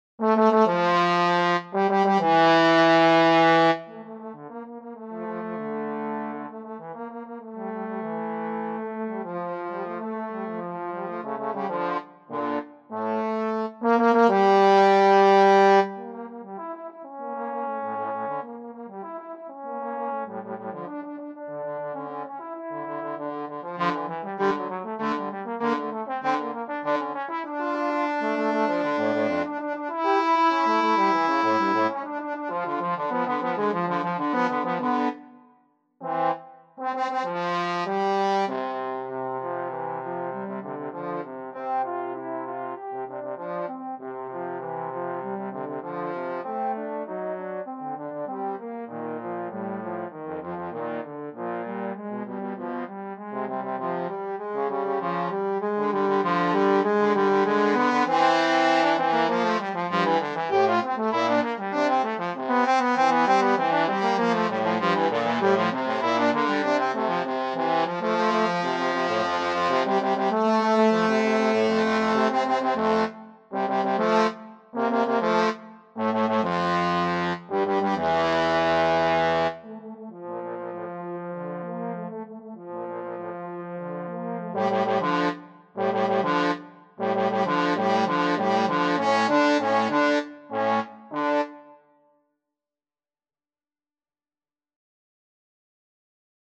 Trombone Duet version
Allegro con brio (=108) =98 (View more music marked Allegro)
2/4 (View more 2/4 Music)
Classical (View more Classical Trombone Duet Music)